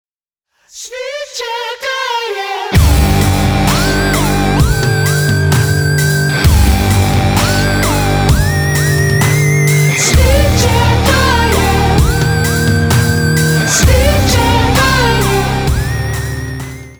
• Качество: 320, Stereo
украинский рок
indie rock